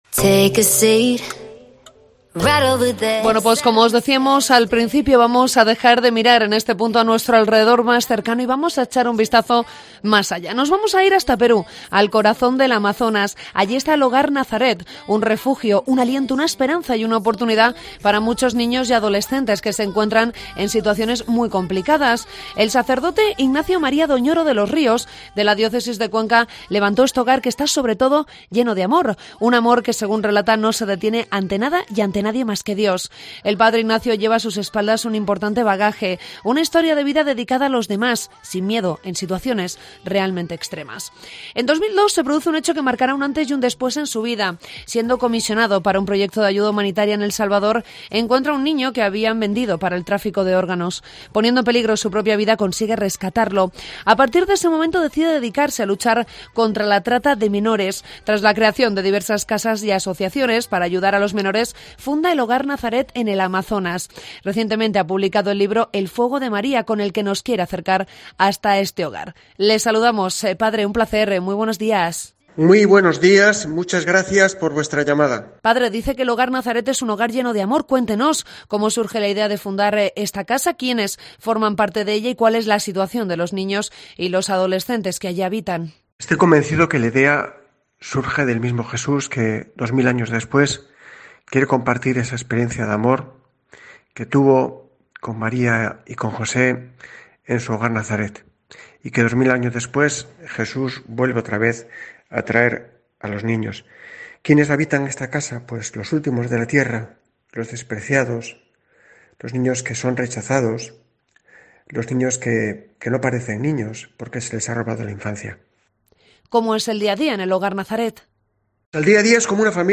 Entrevista con el sacerdote